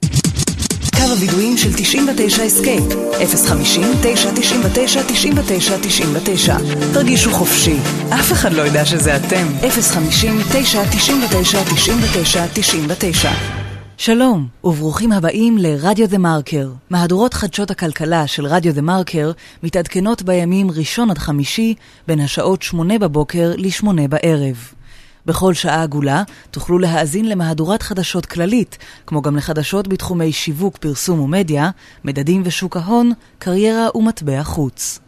Hebrew, Middle Eastern, Female, Home Studio, 20s-30s